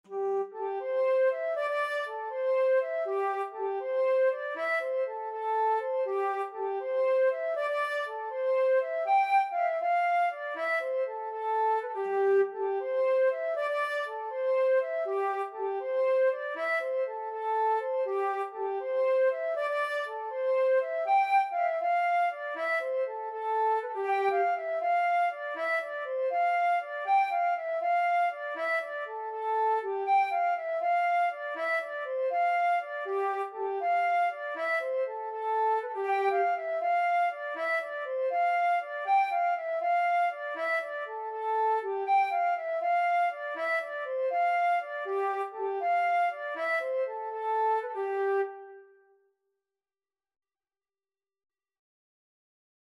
C major (Sounding Pitch) (View more C major Music for Flute )
6/8 (View more 6/8 Music)
G5-G6
Flute  (View more Easy Flute Music)
Traditional (View more Traditional Flute Music)